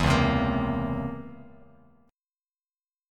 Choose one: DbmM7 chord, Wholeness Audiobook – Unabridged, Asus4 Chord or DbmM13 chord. DbmM13 chord